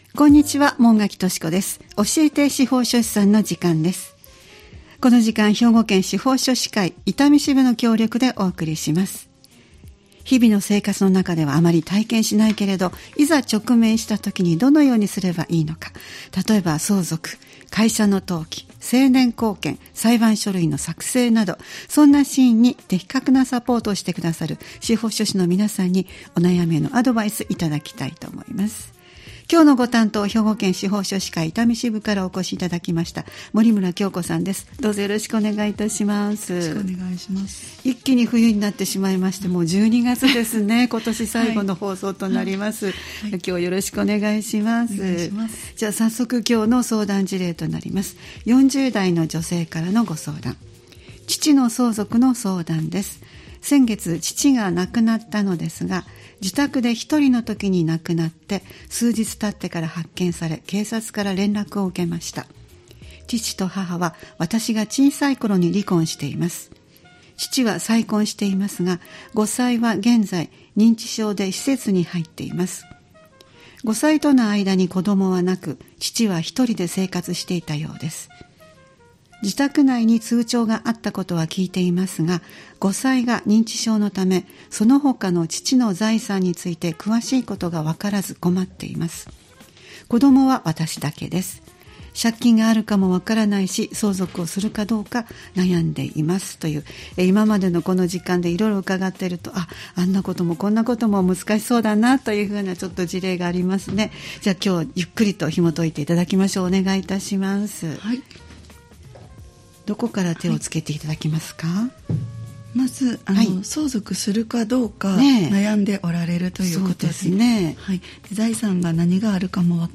毎回スタジオに司法書士の方をお迎えして、相続・登記・成年後見・裁判書類の作成などのアドバイスをいただいています。